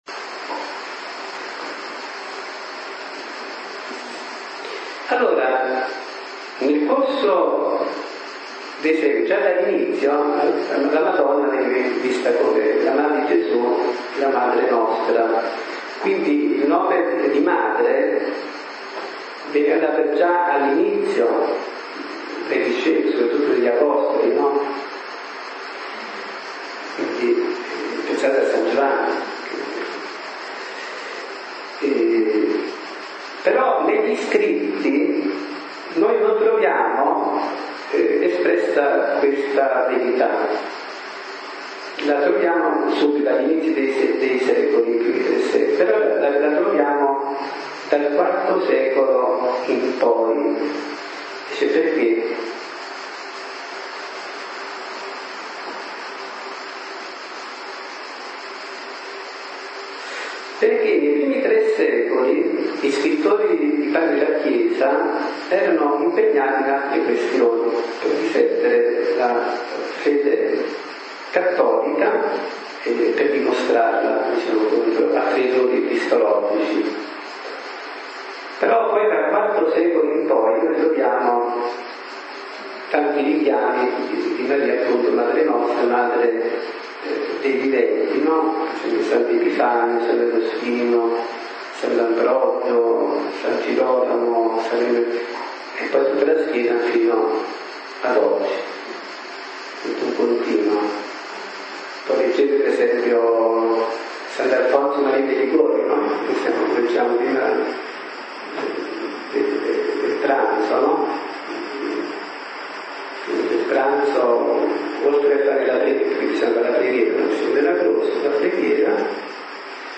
Genere: Catechesi domenicali.